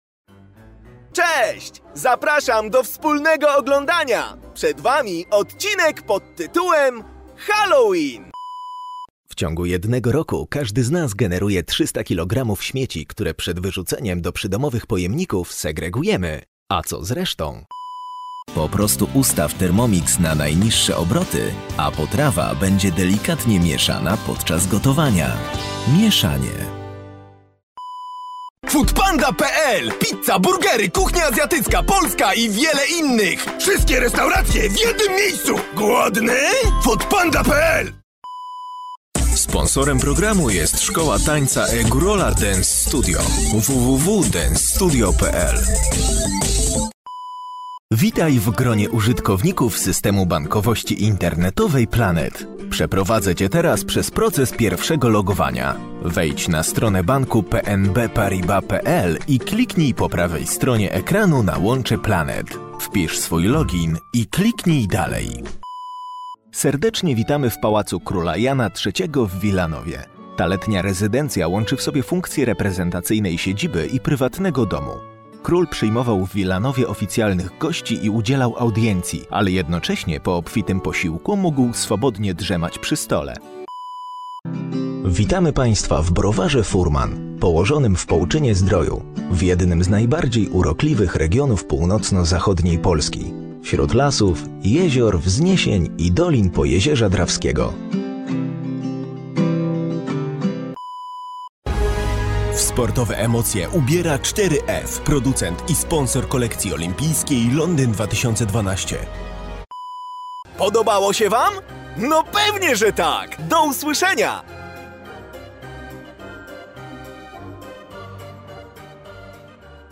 Polish voiceover with dubbing and acting expierence.
polnisch
Sprechprobe: Werbung (Muttersprache):